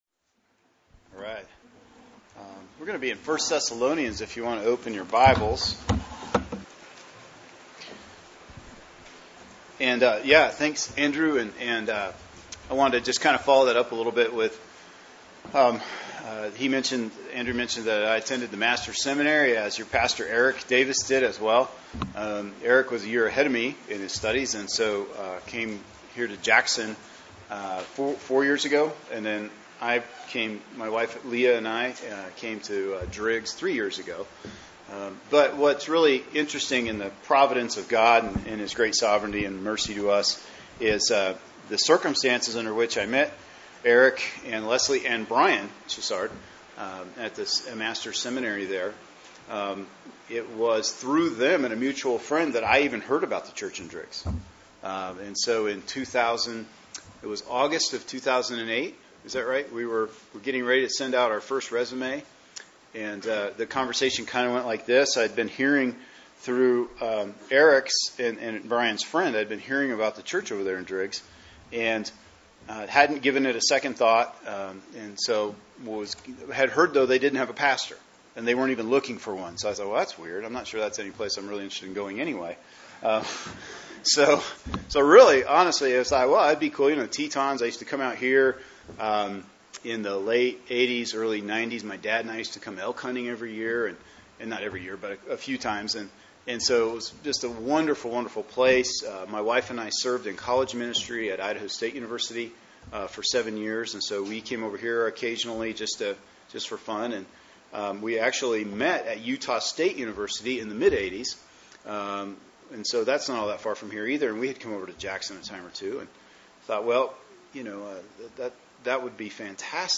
[sermon] 1 Thessalonians 1:1-10 “Signs of Life” | Cornerstone Church - Jackson Hole